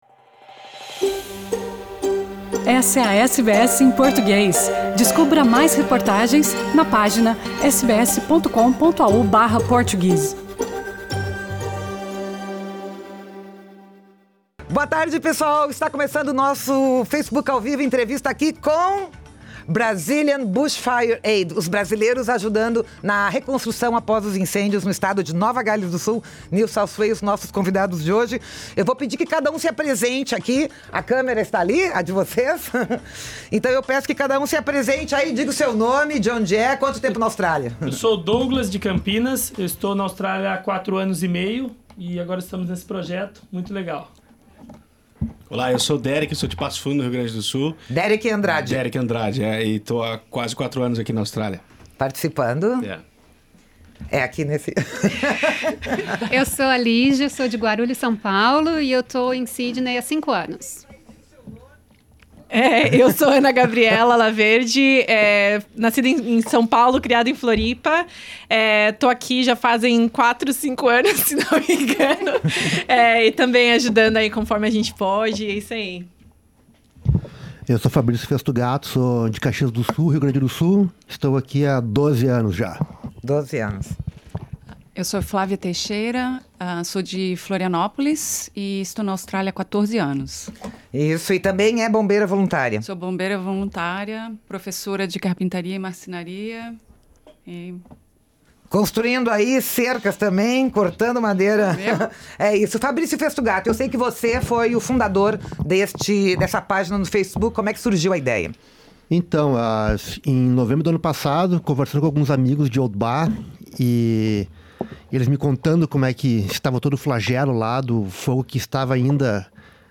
Conversamos com homens e mulheres do grupo BBA/Brazilian Bushfire Aid que, com moto-serra, a facão e a picareta, seguem fazendo trabalho voluntário nas zonas rurais afetadas pelos fogos em Nova Gales do Sul/NSW
na Rádio SBS/Sydney Source